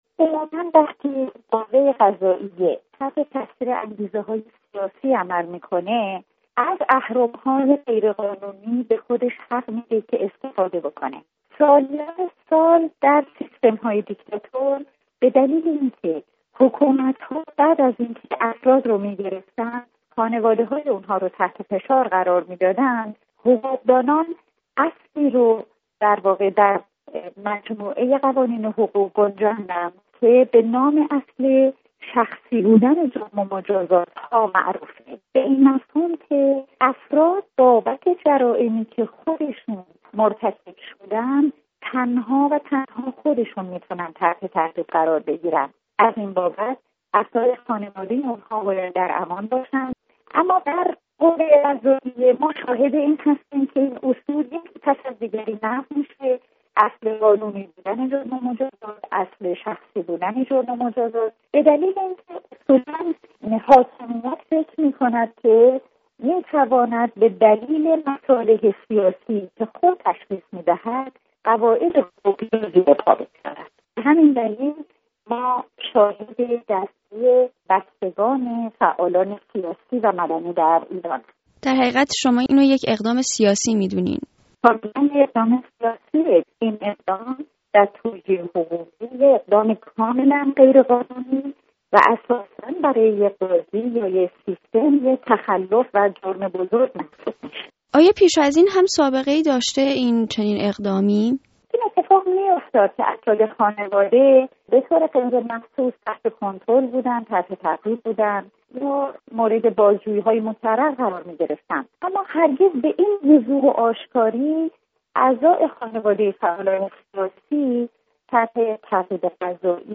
گفت‌وگو با نسرین ستوده؛ وکیل دادگستری در تهران